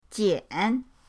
jian3.mp3